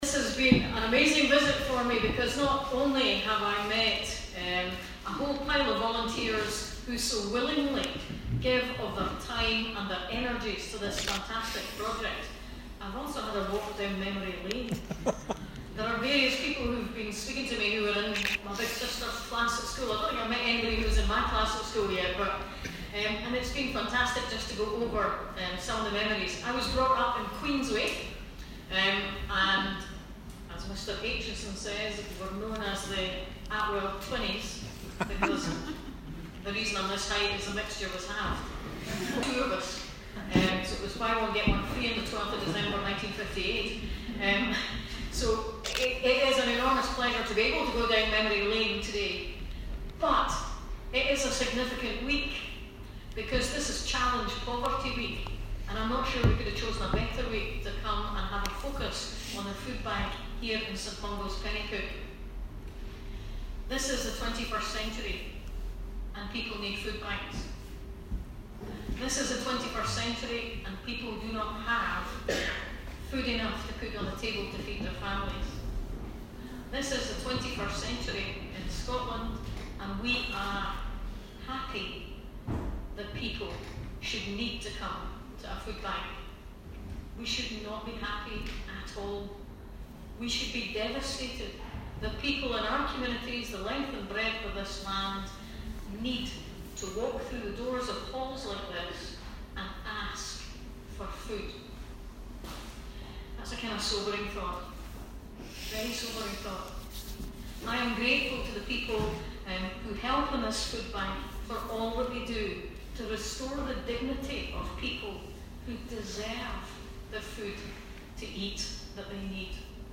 Audience gathering to hear the talks